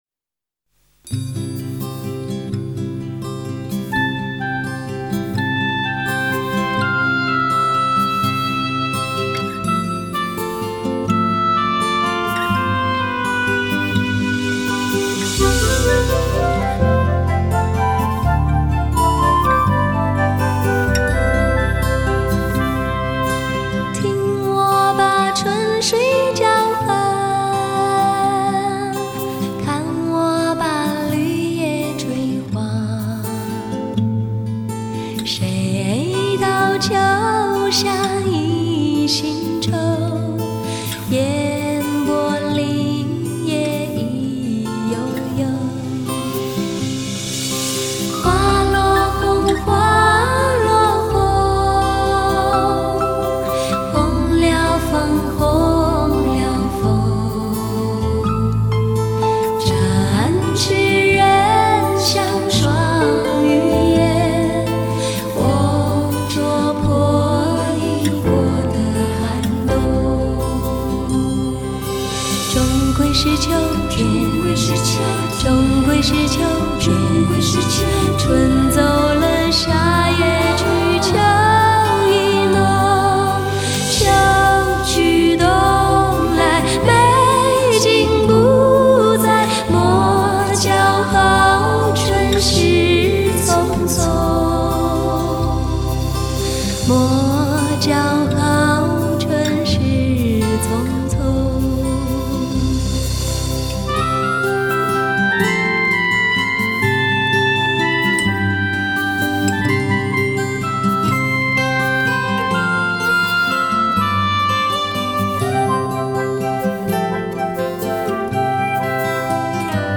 如何寻找一份寂静与解脱……清纯、甜美的歌声，